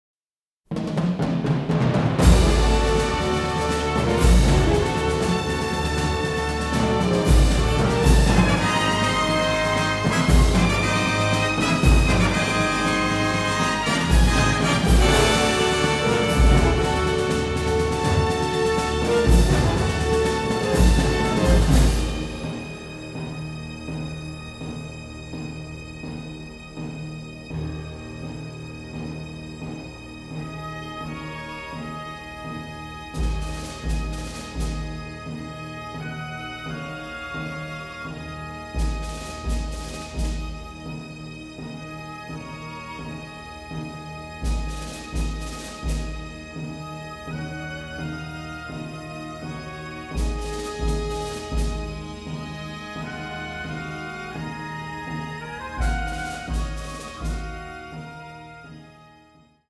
classic war score